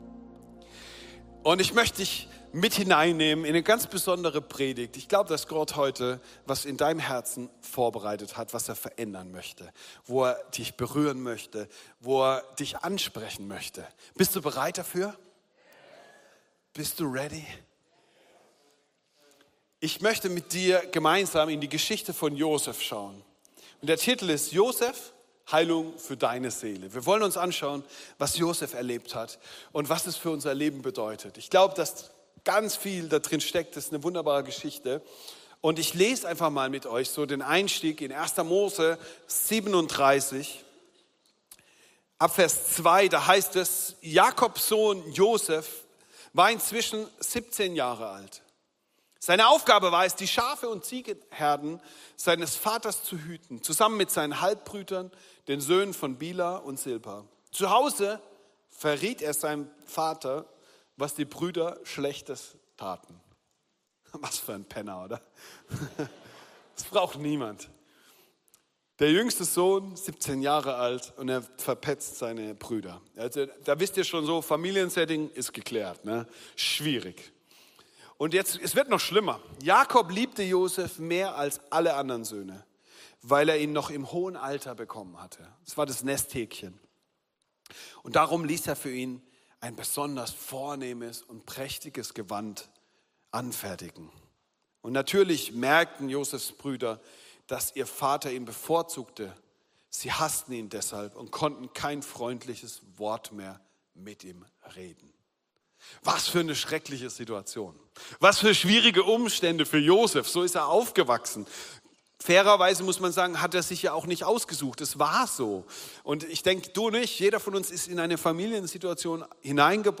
Dienstart: Sonntag